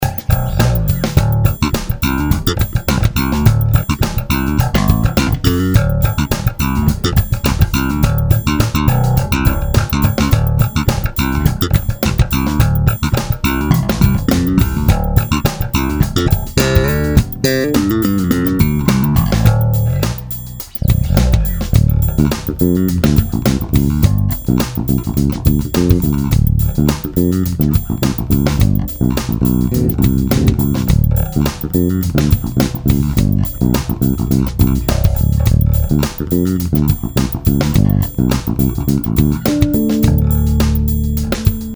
ted ty ukazky :)) EQ je zatim narovno,lehka komprese v kompu. samotny MM a J snimac mozna nahraju pozdeji,ale neni to zadny zazrak :D v ukazkach je blend presne na stredu
MM rozdeleny na singl - ten bliz ke krku + J - slap, pak prsty